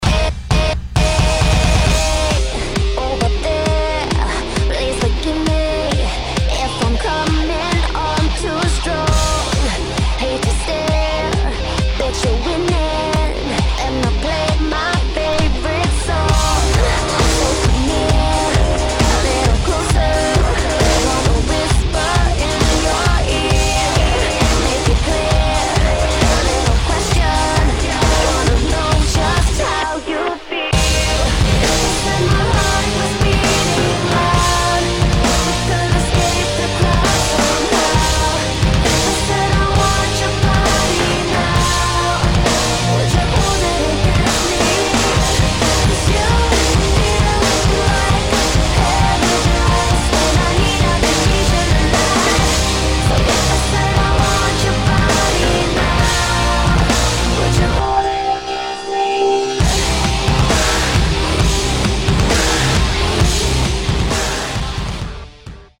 [ POP | HOUSE | DUBSTEP ]